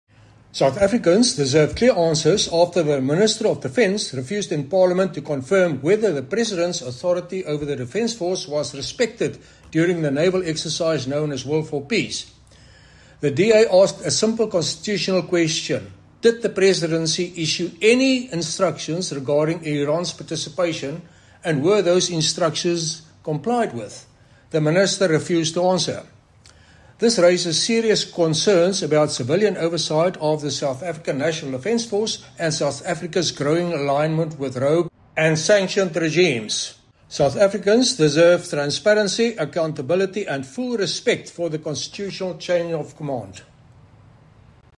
Issued by Chris Hattingh MP – DA Spokesperson on Defence & Military Veterans